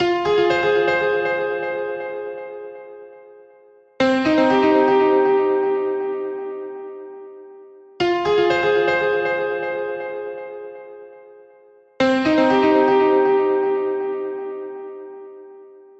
Tag: 120 bpm Chill Out Loops Piano Loops 1.35 MB wav Key : F